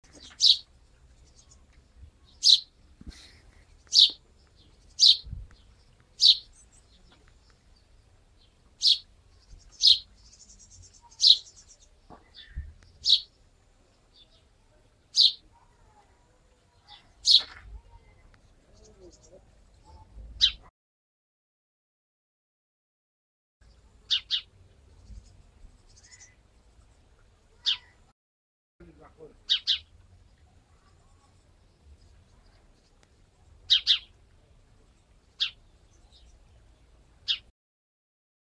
Звуки воробья
Звук радостного воробья на чистом воздухе